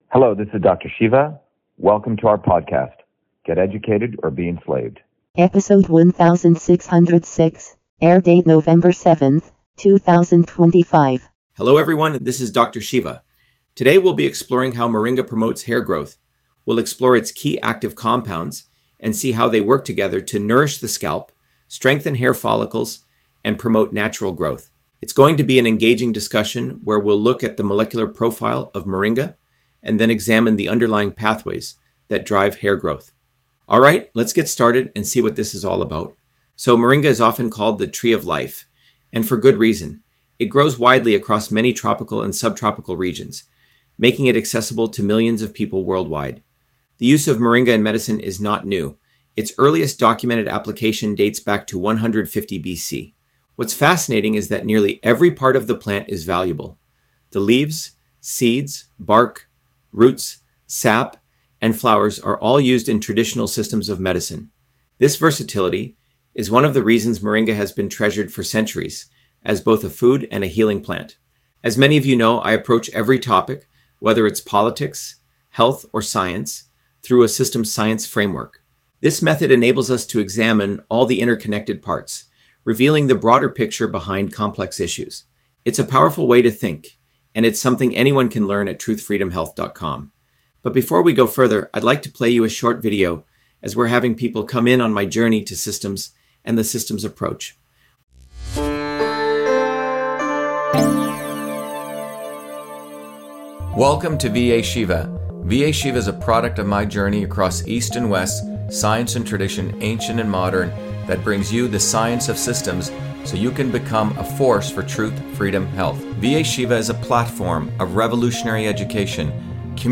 In this interview, Dr.SHIVA Ayyadurai, MIT PhD, Inventor of Email, Scientist, Engineer and Candidate for President, Talks about Moringa on Hair Loss: A Whole Systems Approach